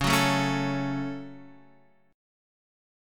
Dbsus4#5 chord